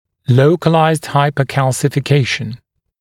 [‘ləukəlaɪzd ˌhaɪpə(u)ˌkælsɪfɪ’keɪʃn][‘лоукэлайзд ˌхайпо(у)ˌкэлсифи’кейшн]локальная гипоминерализация